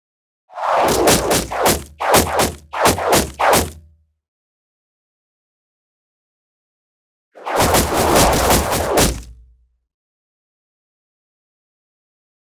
На этой странице вы найдете высококачественные записи звука выстрела из лука, свиста летящей стрелы и ее попадания в мишень.
Стрелы летят и втыкаются в дерево выстрелы из длинного лука